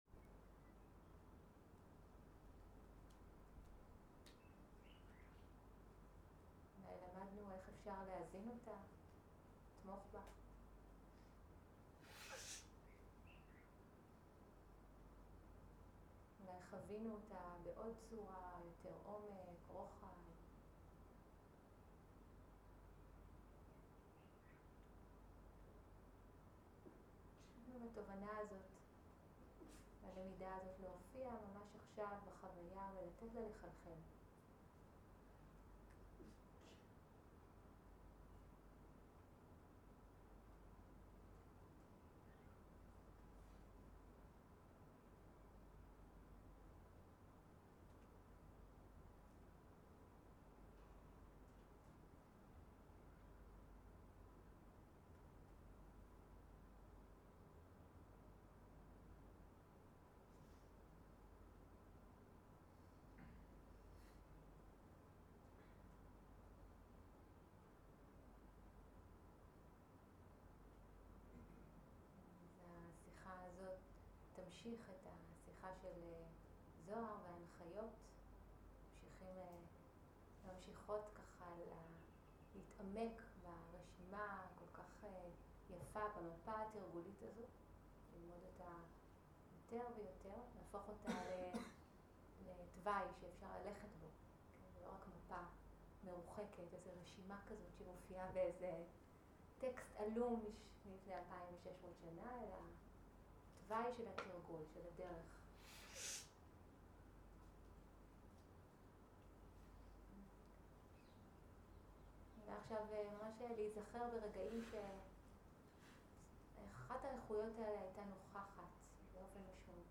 סוג ההקלטה: שיחות דהרמה